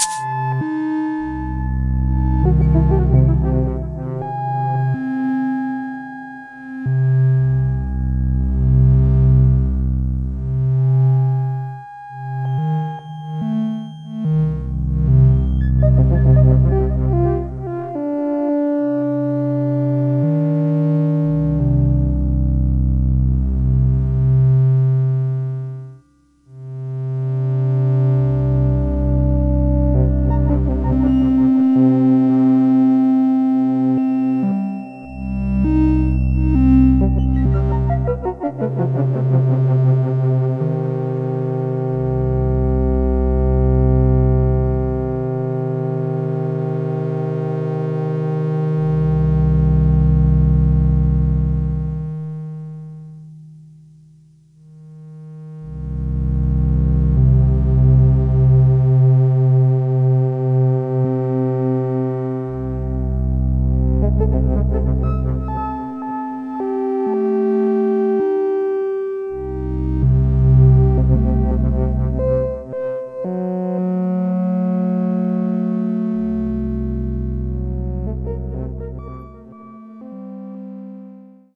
低音总是播放低音。
合成器创建具有不同特征的定义音轨。
Tag: 模拟 EP 电子 生成 合成器 EURORACK 模块化 机的组合物 合成器 噪声